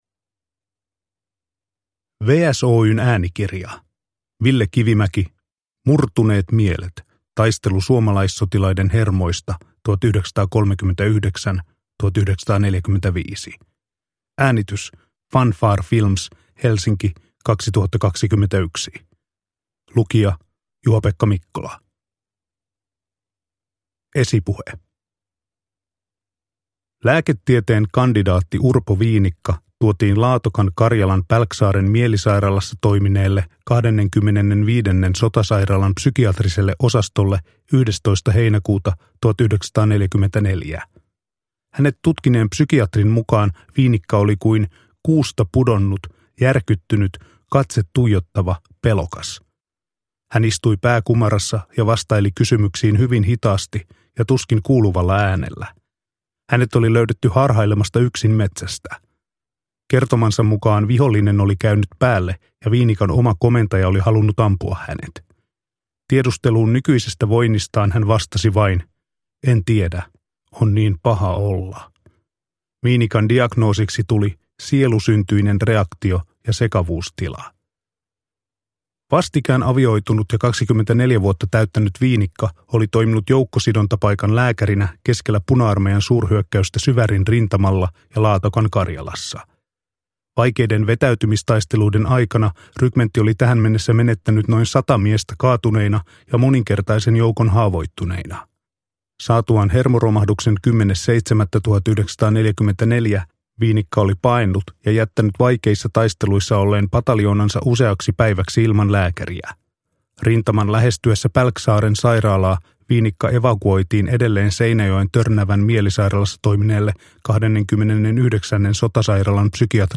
Murtuneet mielet – Ljudbok – Laddas ner